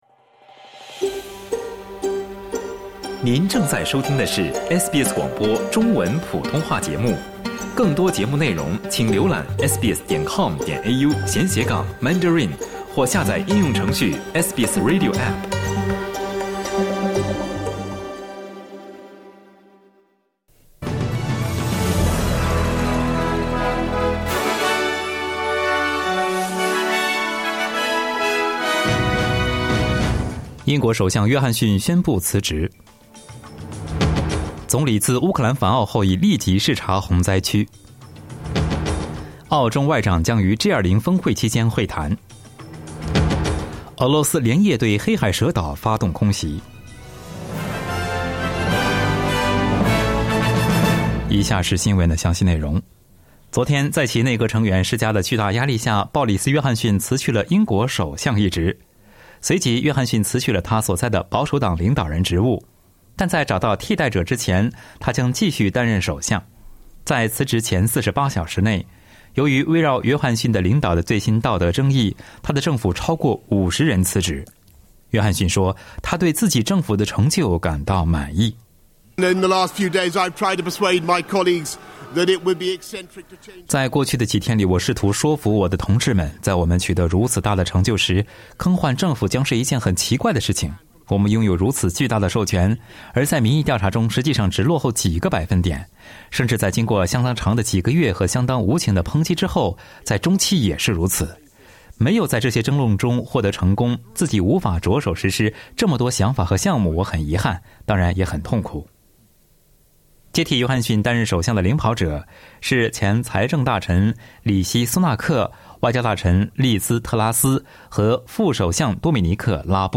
SBS早新闻（7月8日）